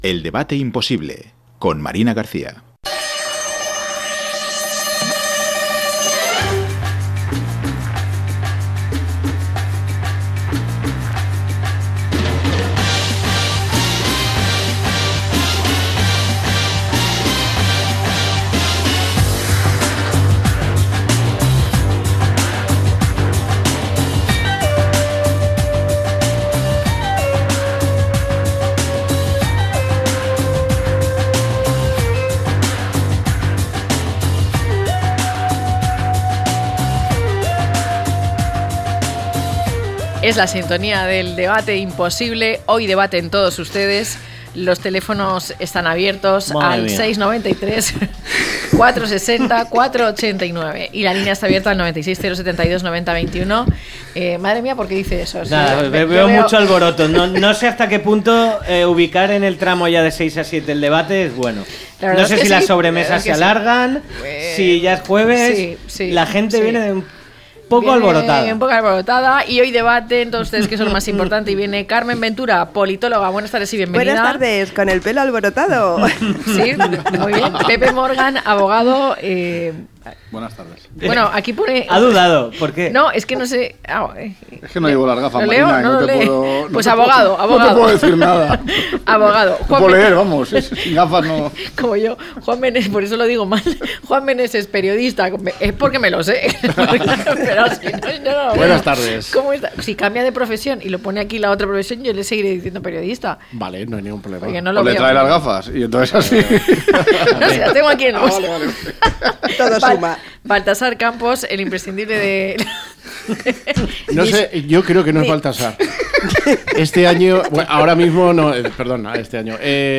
Parejas que no viven bajo el mismo techo, a debate